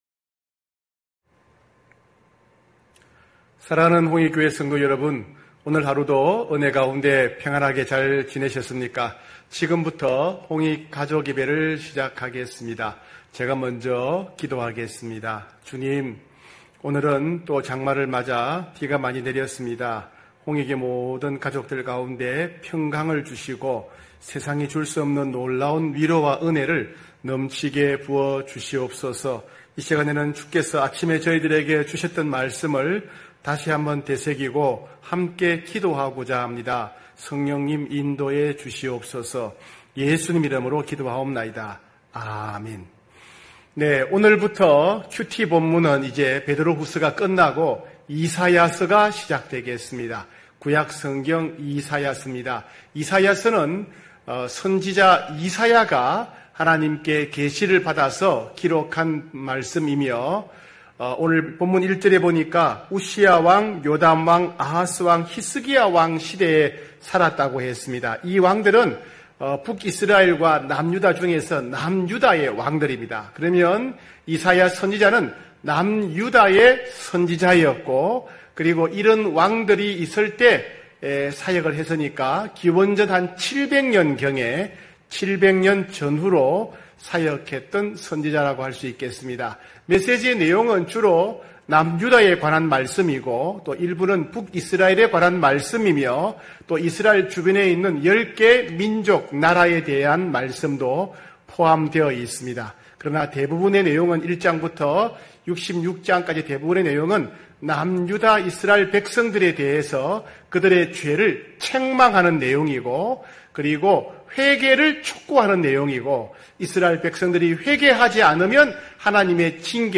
9시홍익가족예배(7월10일).mp3